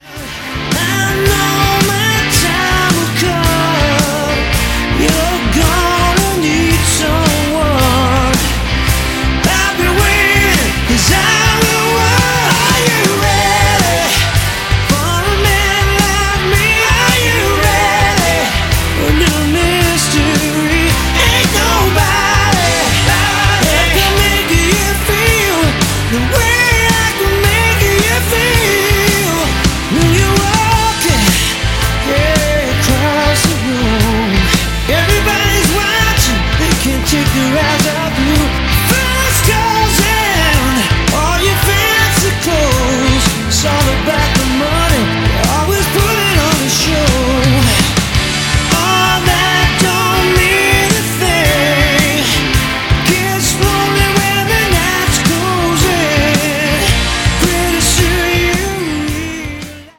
Lead Vocals
Guitars & Keyboards
Bass
Drums